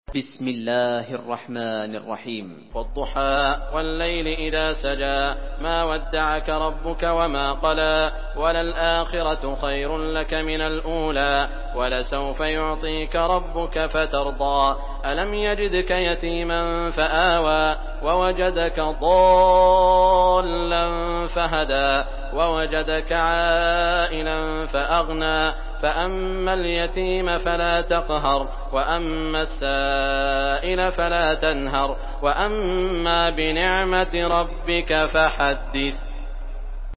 Surah আদ্ব-দ্বুহা MP3 in the Voice of Saud Al Shuraim in Hafs Narration
Listen and download the full recitation in MP3 format via direct and fast links in multiple qualities to your mobile phone.